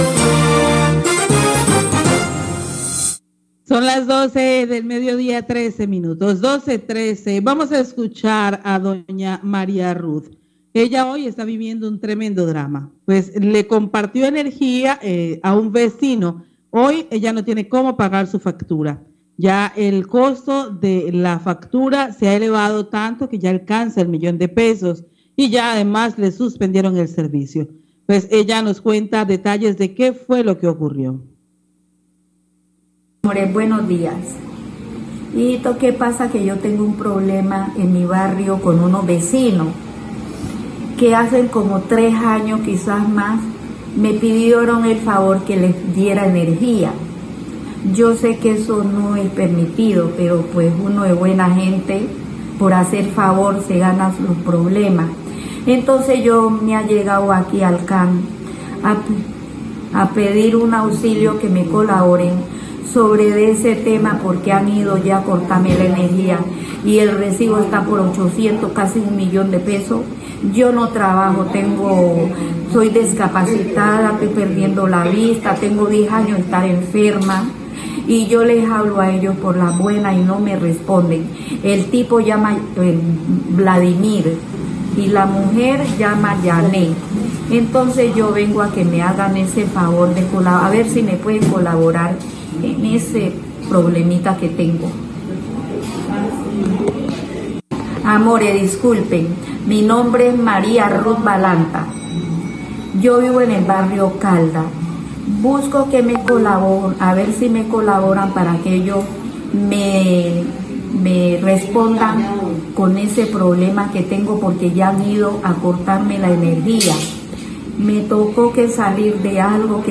Radio
Oyente del sector del barrio Caldas pide ayuda debido a que compartió energía a unos vecinos que se niegan a pagar; el costo en la factura de energía  ya se encuentra aproximadamente 800.000 pesos y están a punto de suspender el servicio ya que no cuenta con el recurso para hacer el pago por ser una persona enferma.